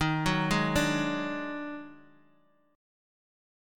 EbmM7bb5 Chord